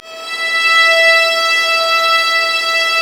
Index of /90_sSampleCDs/Roland L-CD702/VOL-1/STR_Vlas Bow FX/STR_Vas Sul Pont